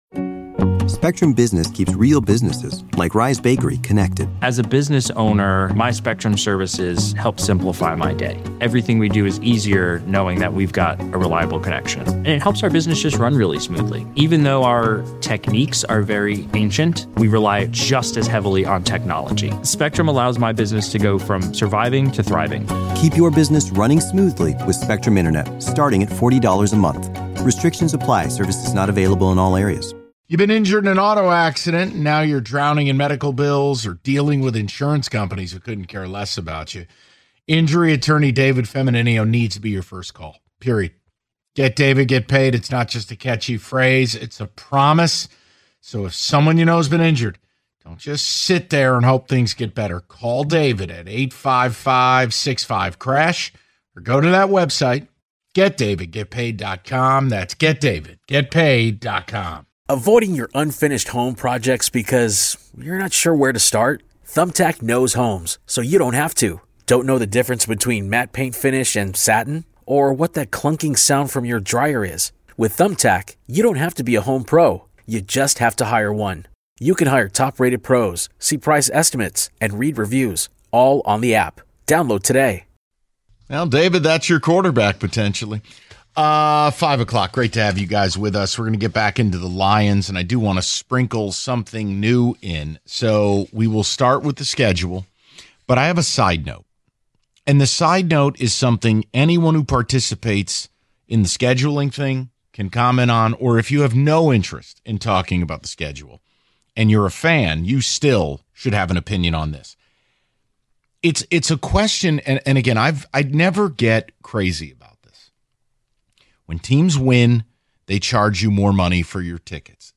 They speak to some fans who share their thoughts